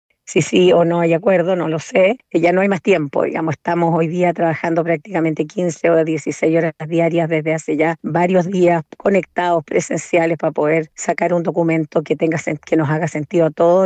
En conversación con Radio Bío Bío, la comisionada y senadora republicana por La Araucanía, Carmen Gloria Aravena, reveló que de momento no se estaría llegando a un acuerdo.